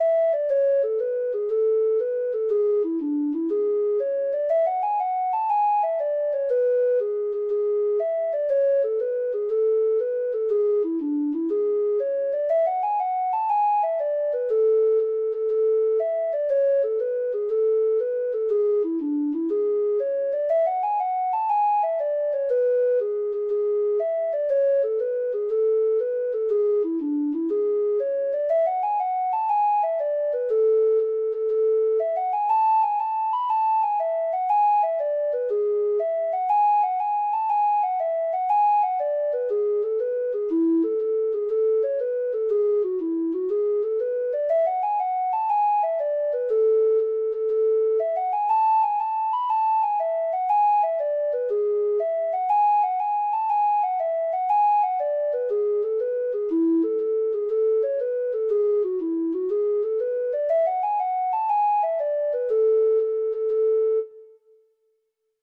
Hornpipes